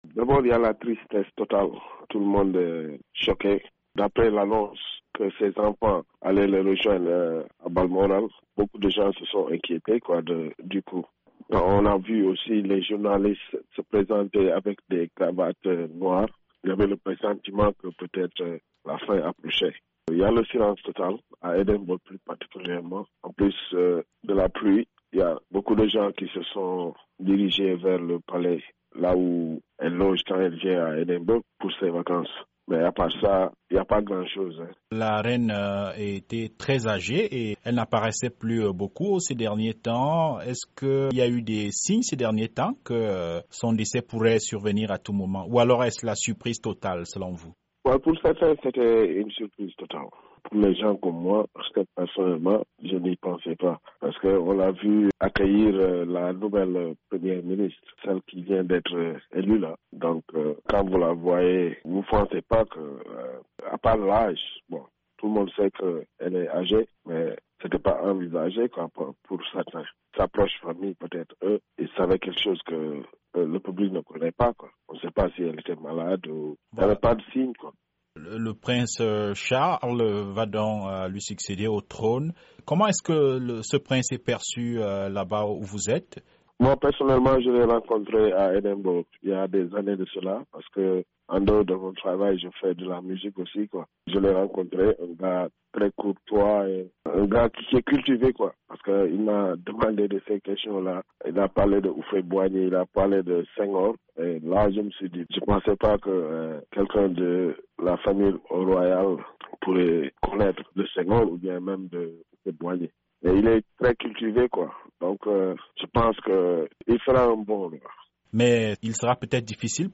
Témoignage: Charles III parlait de Senghor et d'Houphouët Boigny